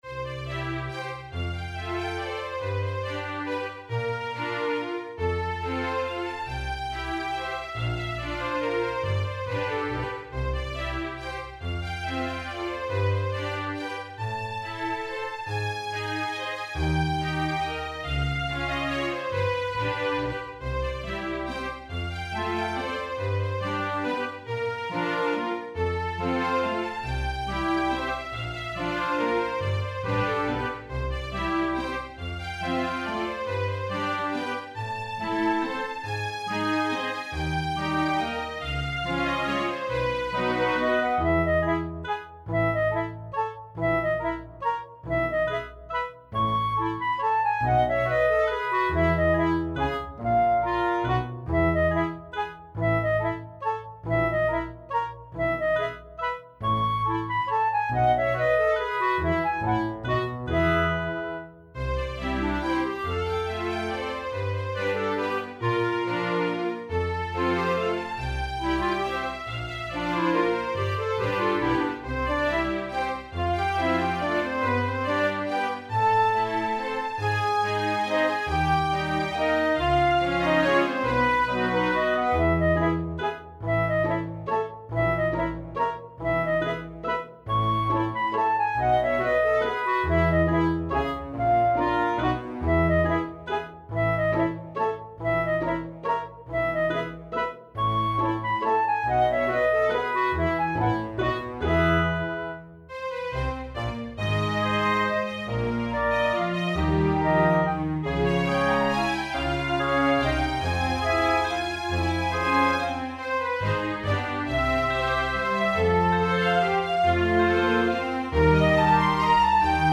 Genre - classical, orchestral, Australian composition